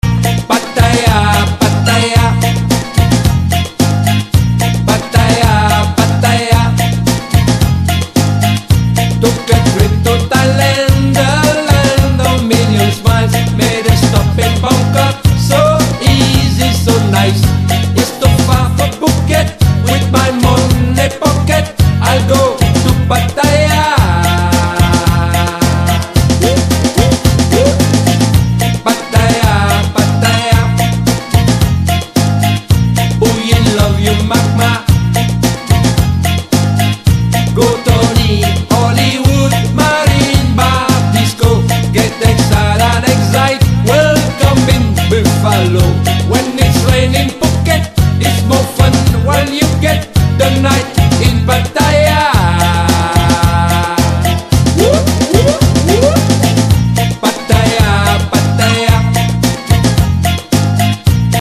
• Качество: 128, Stereo
веселые